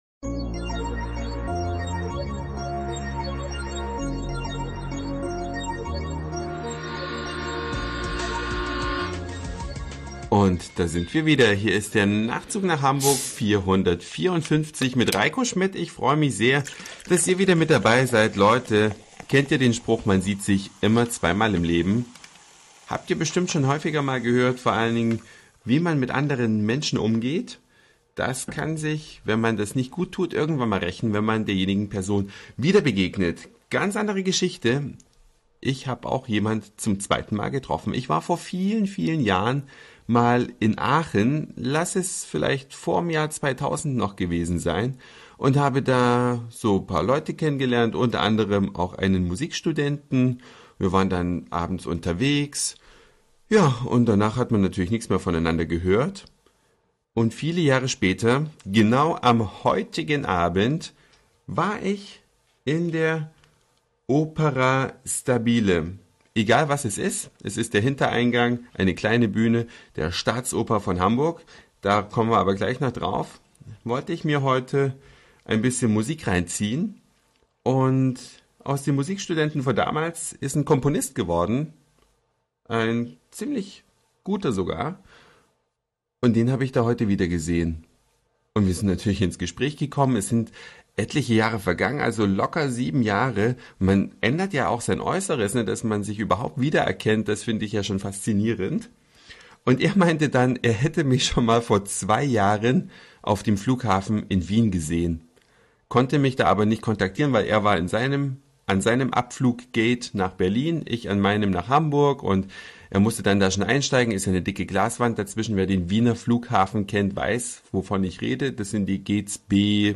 Eine Reise durch die Vielfalt aus Satire, Informationen, Soundseeing und Audioblog.
the link to today's music - Musik mit Deoroller, Senfglas und
Donnerblech, zum Teil mit elektronischer Unterstützung.